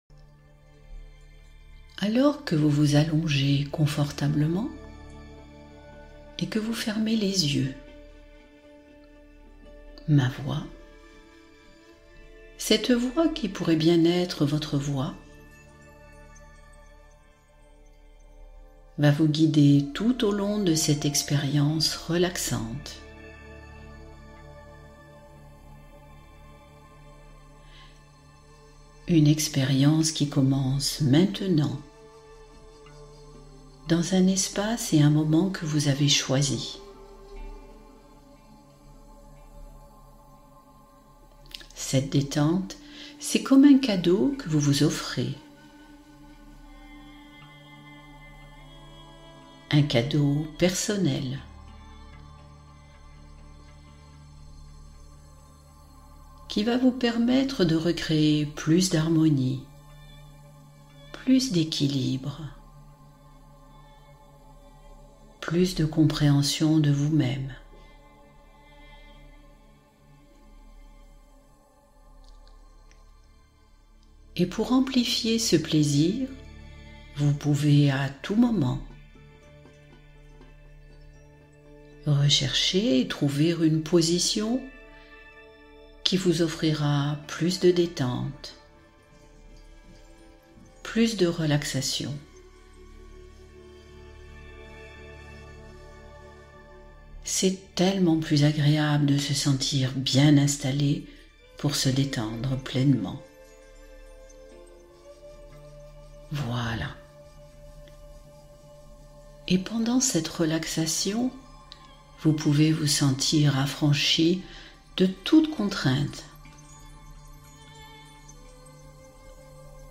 Alléger le mental — Hypnose pour apaiser les pensées